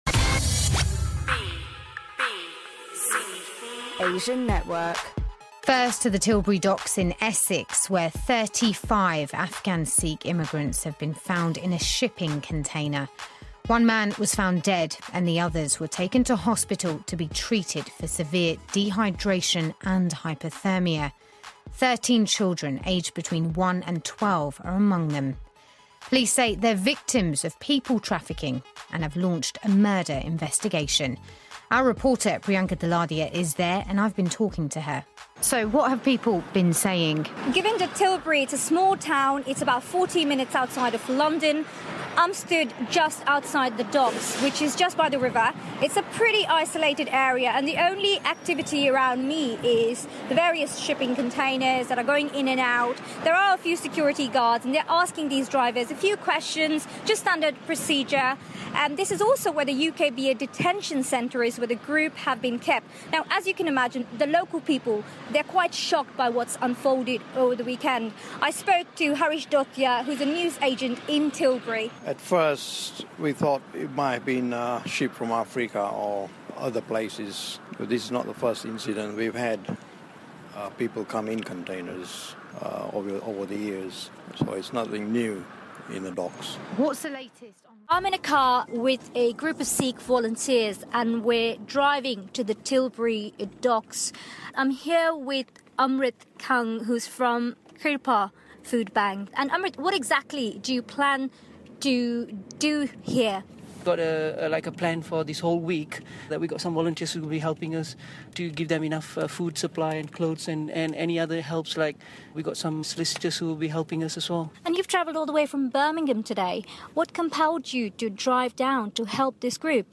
BBC Asian Network coverage. On location reporting for leading story.